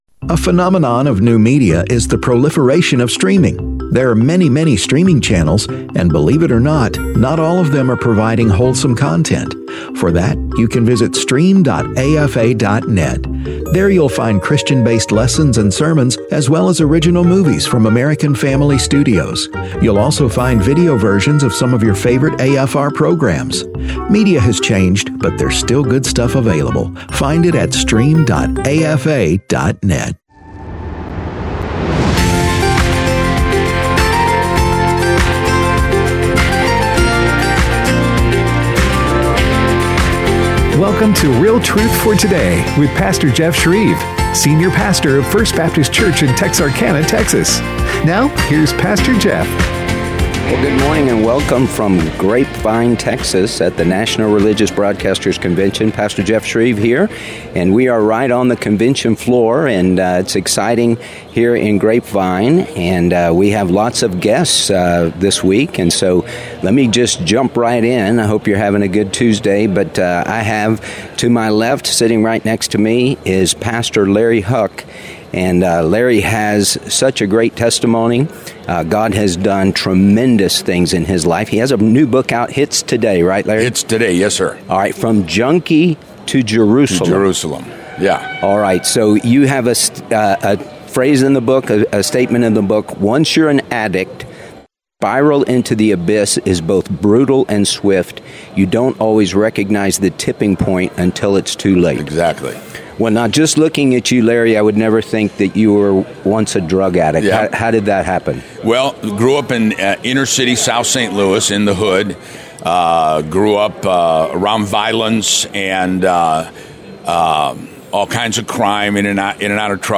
Live From NRB 2025